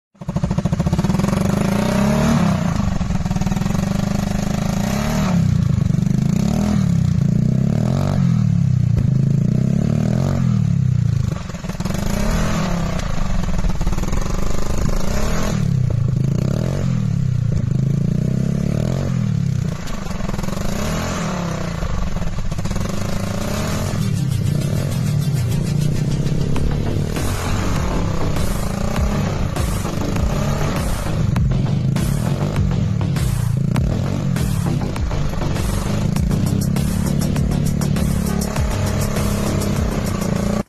Test Sound Tridente Scorpion with sound effects free download
Test Sound Tridente Scorpion with Honda BeAT Scooter